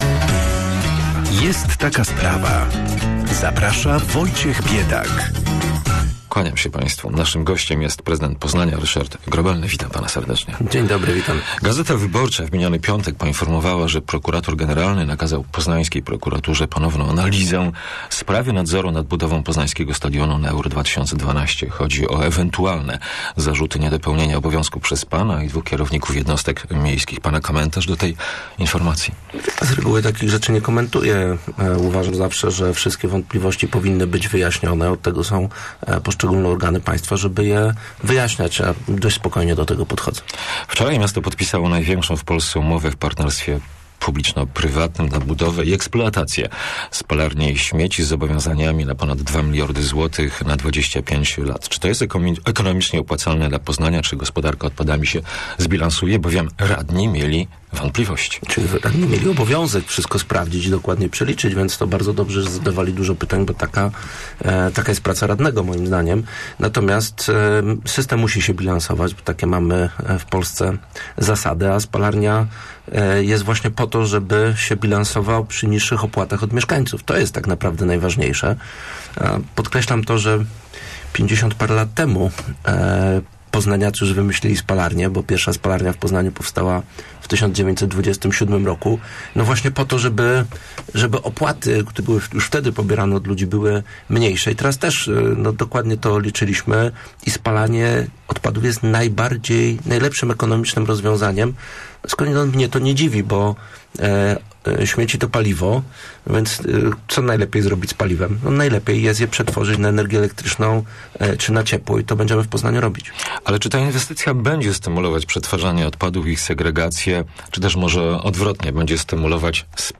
Prezydent Poznania, Ryszard Grobelny, w rozmowie z Radiem Merkury dystansuje się od idei wiernej odbudowy Pomnika Wdzięczności i nie wyklucza starań miasta o tytuł Zielonej Stolicy Europy.